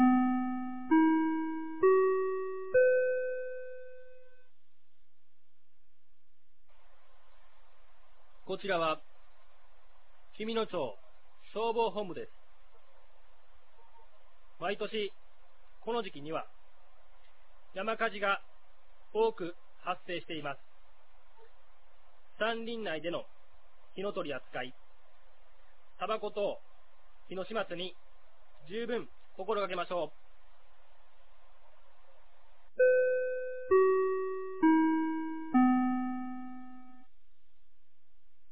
2021年05月01日 16時00分に、紀美野町より全地区へ放送がありました。